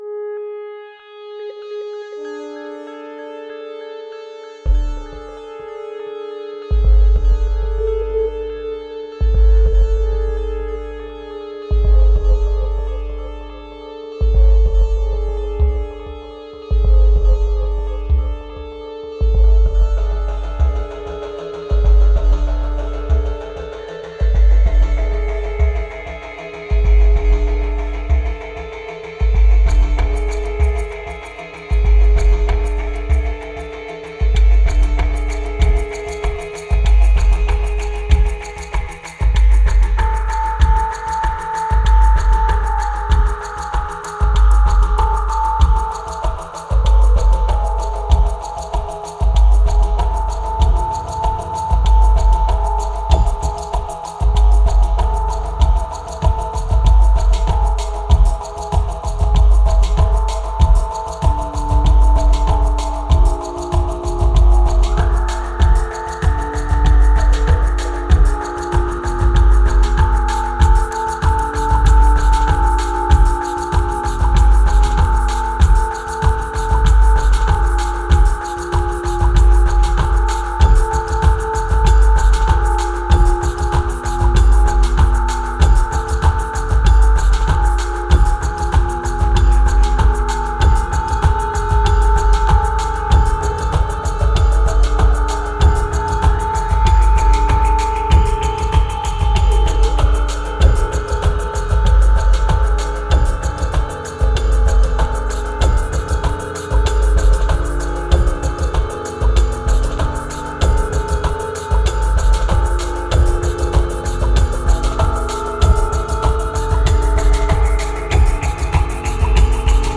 1642📈 - 2%🤔 - 96BPM🔊 - 2013-03-16📅 - -72🌟